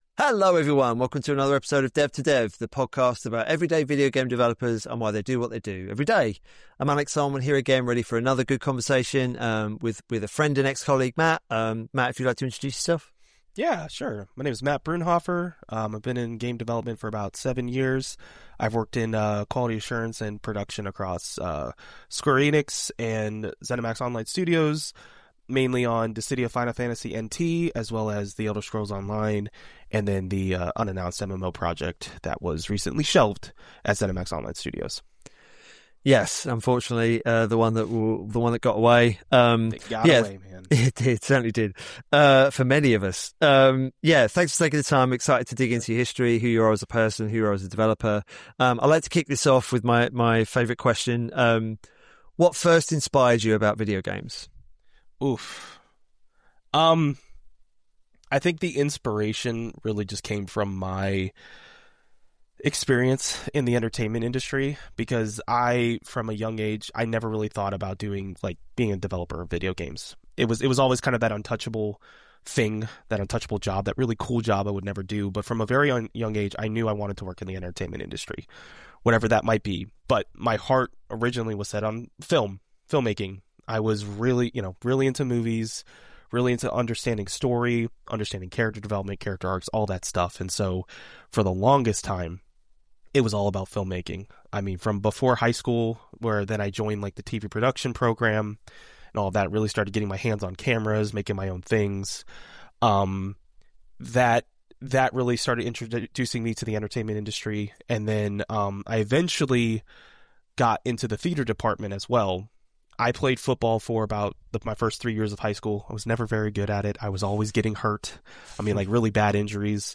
Each week, the podcast aims to offer thoughtful, positive conversations about connection, creativity, and the human side of game development, providing insight into both the rewards and personal challenges of making video games a livelihood.